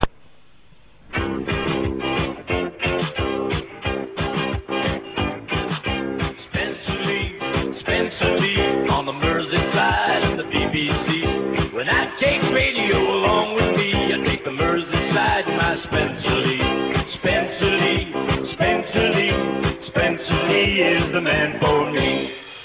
The first Internet interview with JOHN STEWART